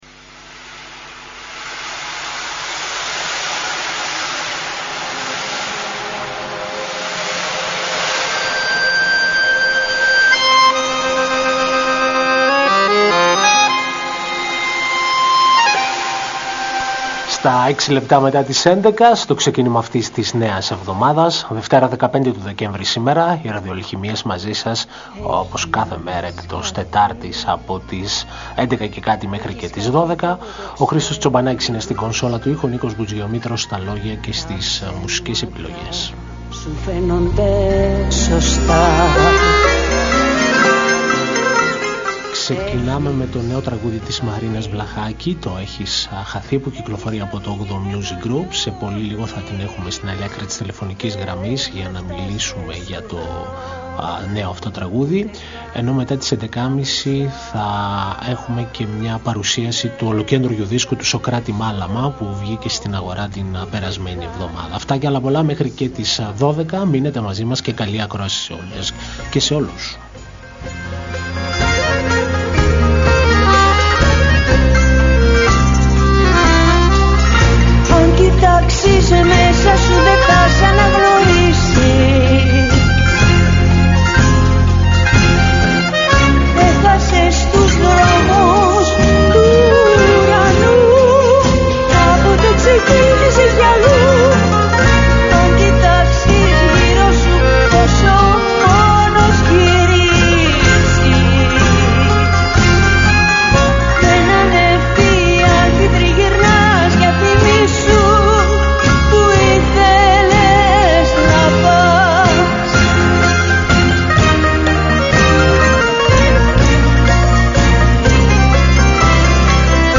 Μια εκπομπή μουσικής και λόγου διανθισμένη με επιλογές από την ελληνική δισκογραφία.